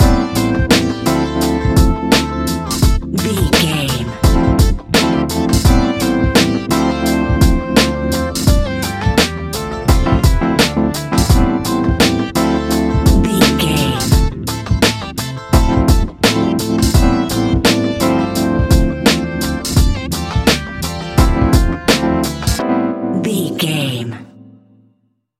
Ionian/Major
A♭
chilled
laid back
Lounge
sparse
chilled electronica
ambient
atmospheric